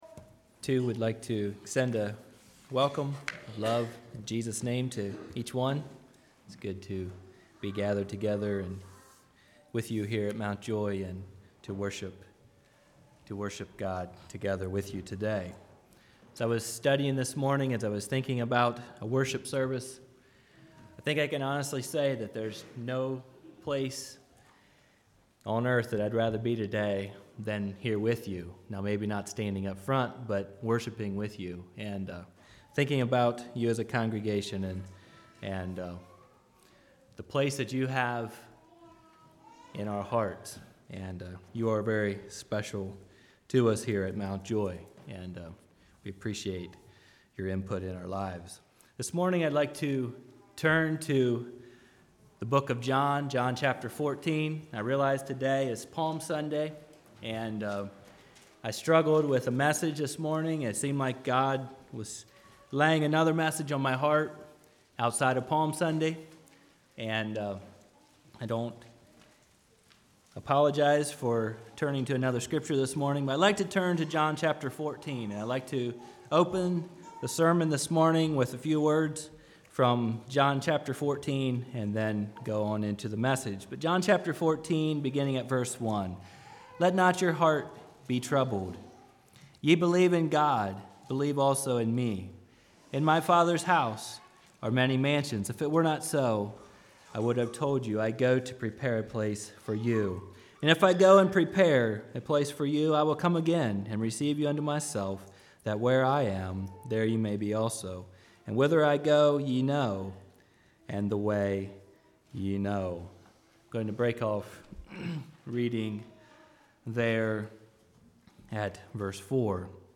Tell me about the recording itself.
He refutes several ideas that are popular among so-called christianity today including the idea that God is to merciful to send anyone to hell and that there will be a chance to repent after we die. (Has a few gaps due to visuals).